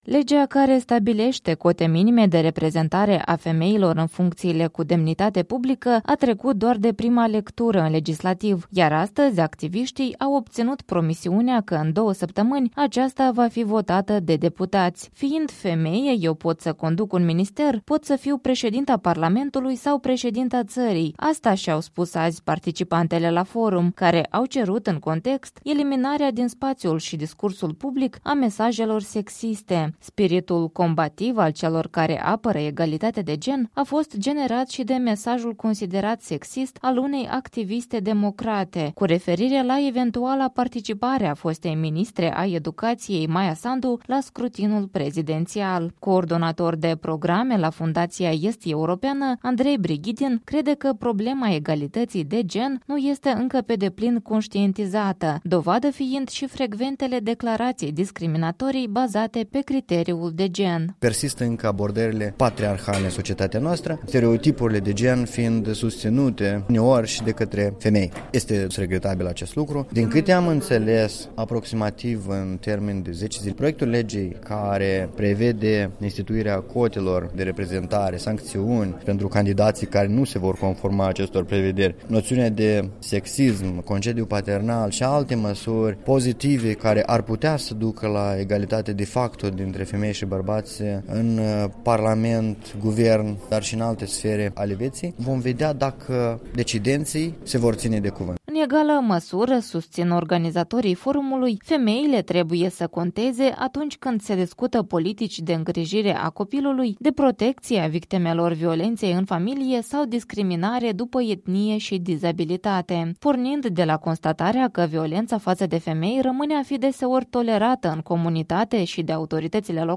O conferință la Chișinău.